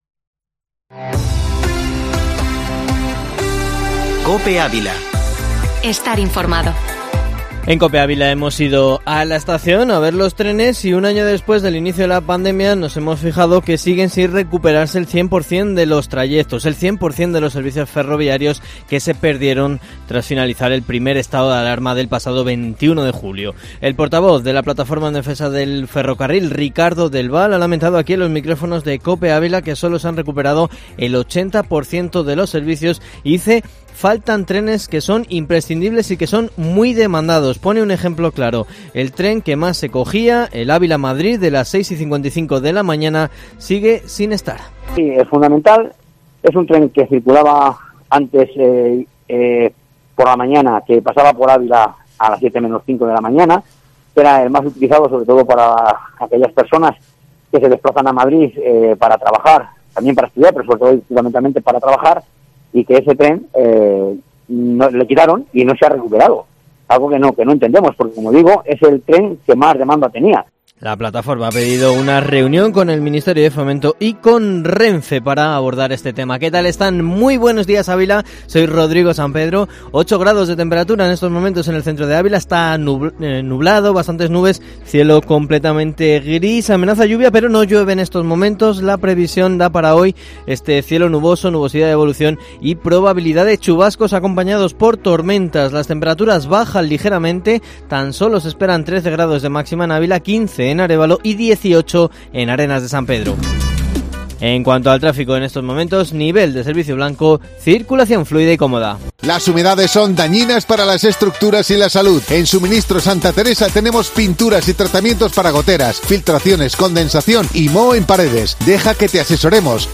Informativo matinal Herrera en COPE Ávila 15/04/2021